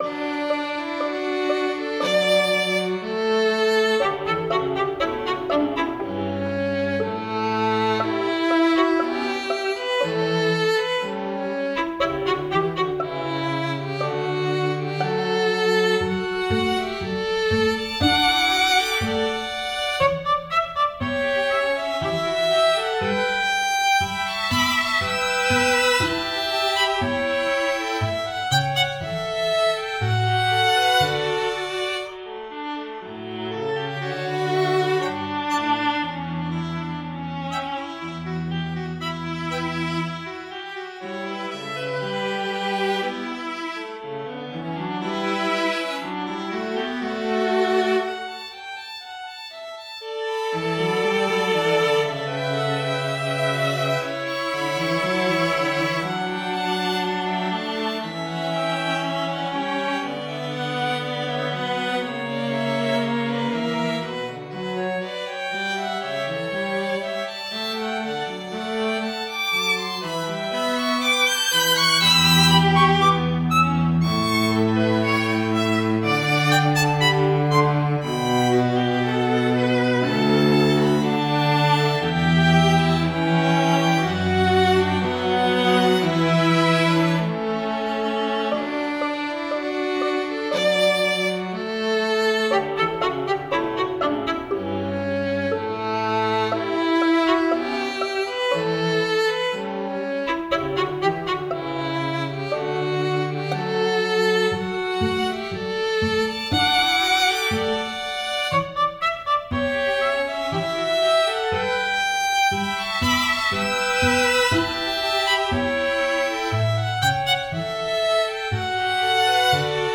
String quartet - 1st movement
Hi folks, I'm back to composing after an inspiration drought of several months, that I attempted to break this week 🙂 This is an allegro moderato, first movement of a string quartet that will have three of them. It's not aimed at being technical or something, just pleasant to one's ears.